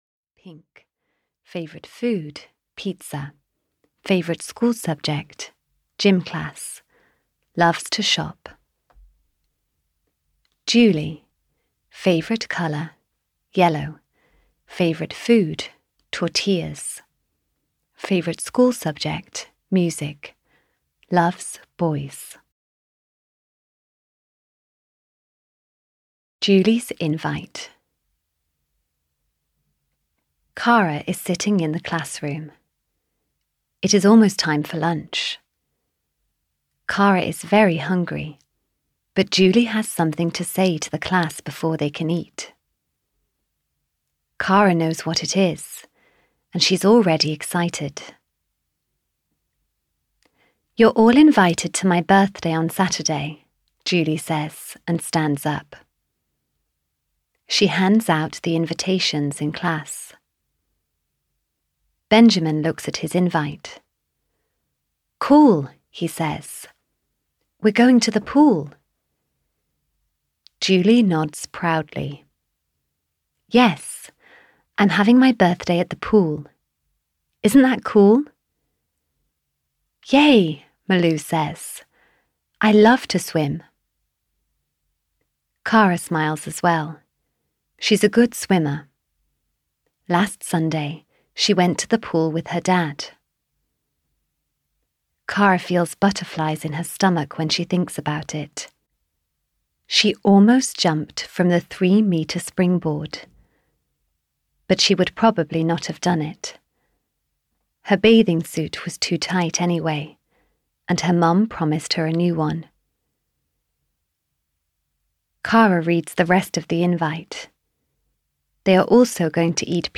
Audio knihaK for Kara 14: Am I Fat, Mom? (EN)
Ukázka z knihy